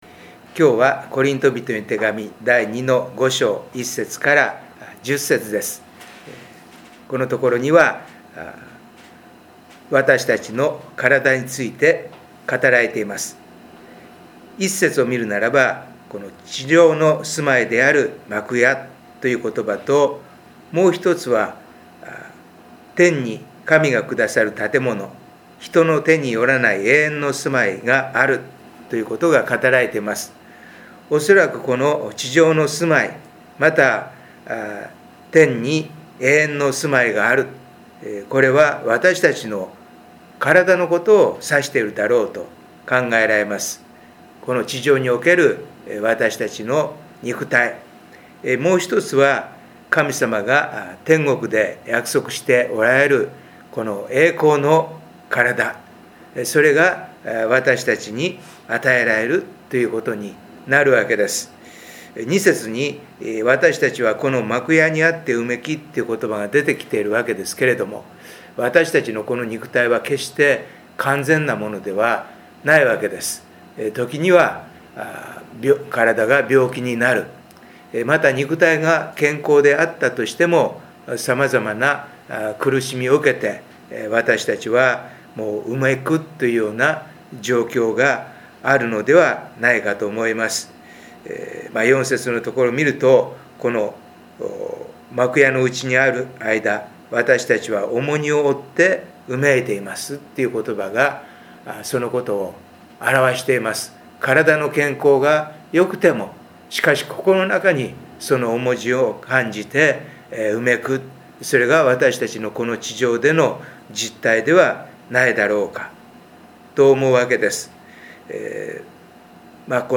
第四主日伝道礼拝
音声メッセージです。